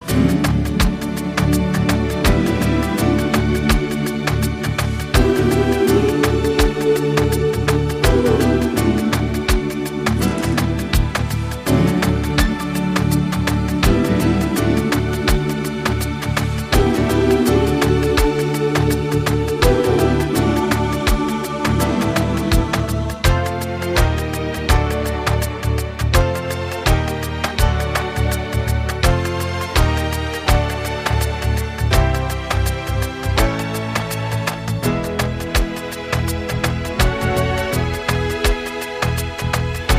без слов
инструментальные , госпел